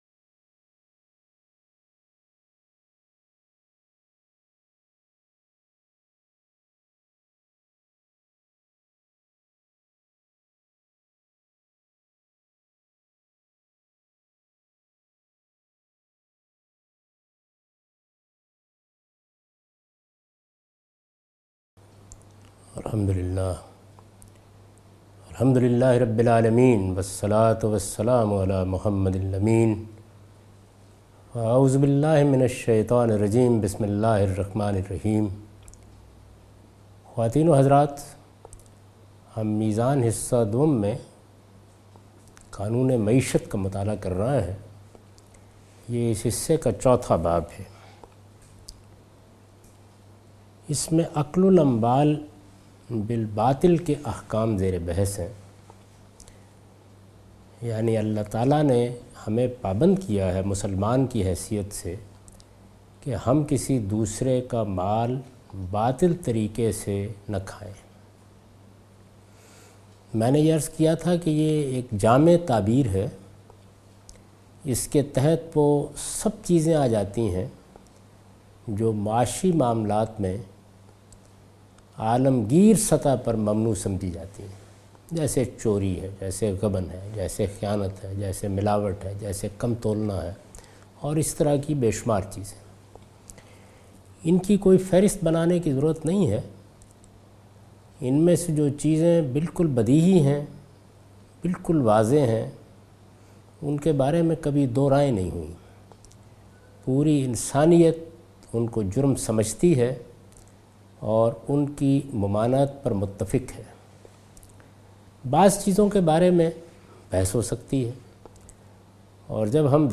Javed Ahmad Ghamidi teaching from his book Meezan. In this lecture he teaches from the chapter "Qanoon e Maeshat".